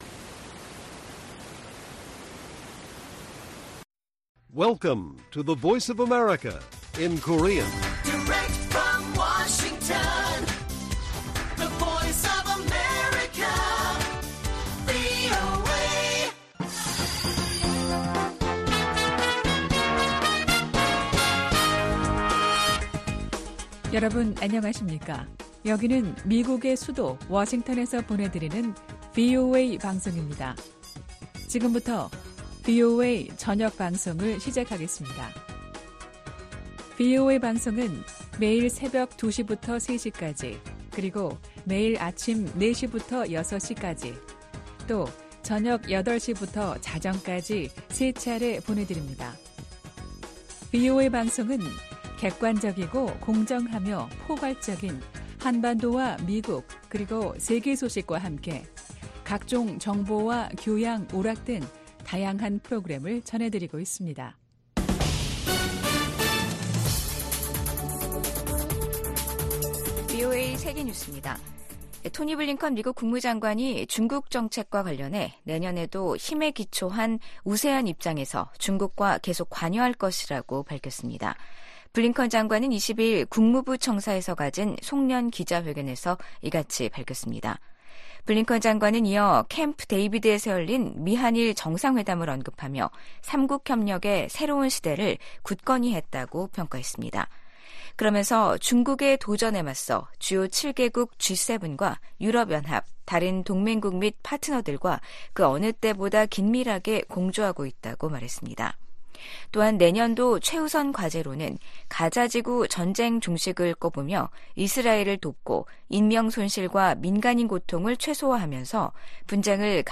VOA 한국어 간판 뉴스 프로그램 '뉴스 투데이', 2023년 12월 21일 1부 방송입니다. 유엔 총회가 북한의 조직적이고 광범위한 인권 침해를 규탄하는 결의안을 19년 연속 채택했습니다. 김정은 북한 국무위원장은 어디 있는 적이든 핵 도발에는 핵으로 맞서겠다고 위협했습니다. 토니 블링컨 미 국무장관이 내년에도 중국에 대한 견제와 관여 전략을 병행하겠다는 계획을 밝혔습니다.